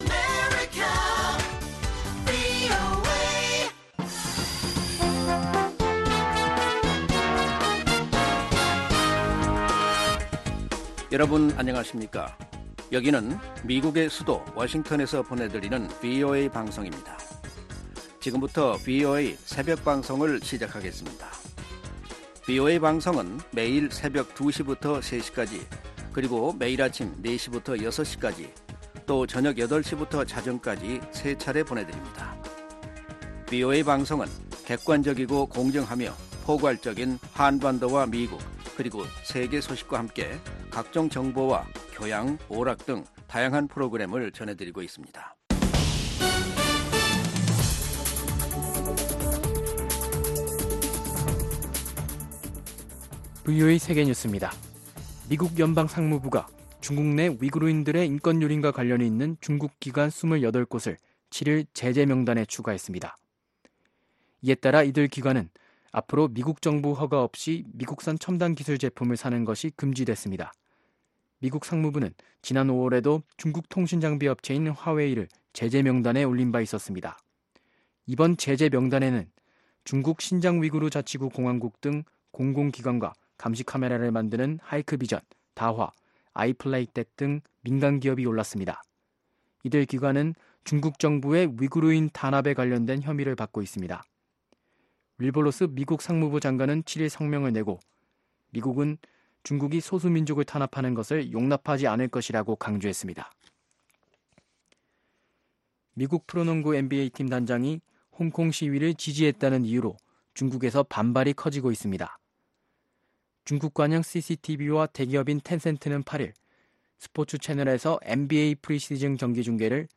VOA 한국어 '출발 뉴스 쇼', 2019년 10월 9일방송입니다. 미국은 북한의 최종적이고 완전히 검증된 비핵화를 모색하고 있으며 북한은 몇 주안에 그 방법론을 제시하길 바란다고 국무부 고위관리가 밝혔습니다. 미-북 실무협상의 결렬은 김정은 북한 국무위원장의 비현실적 기대감 때문이라고 미국의 전직관리들이 지적했습니다.